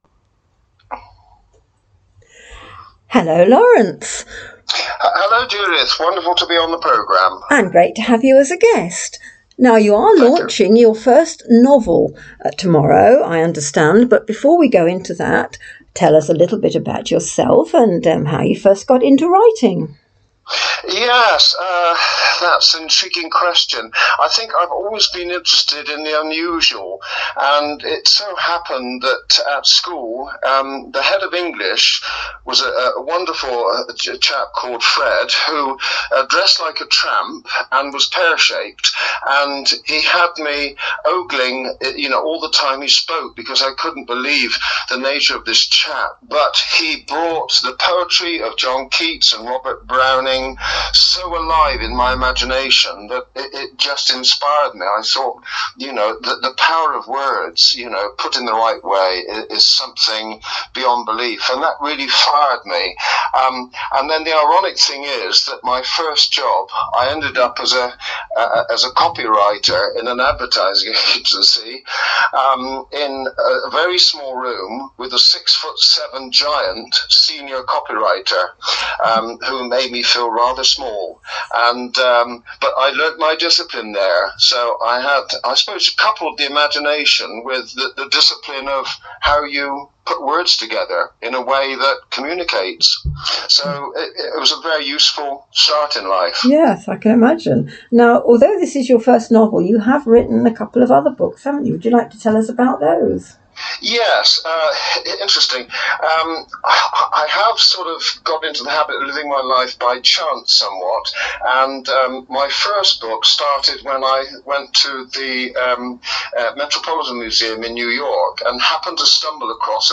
on Feelgood Radio which covers his writing background and the ideas that inspired his books.